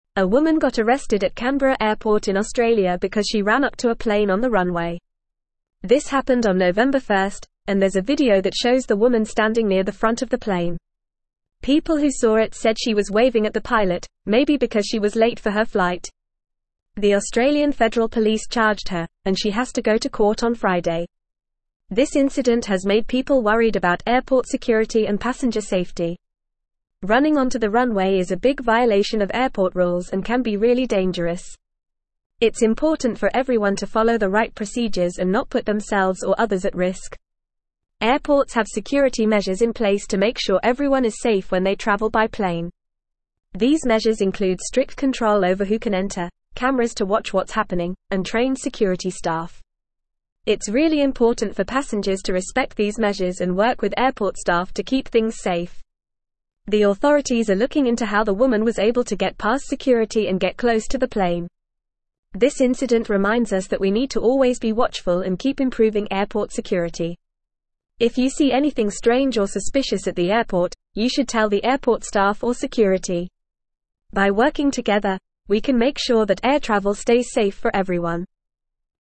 Fast
English-Newsroom-Upper-Intermediate-FAST-Reading-Woman-Arrested-for-Running-onto-Tarmac-at-Canberra-Airport.mp3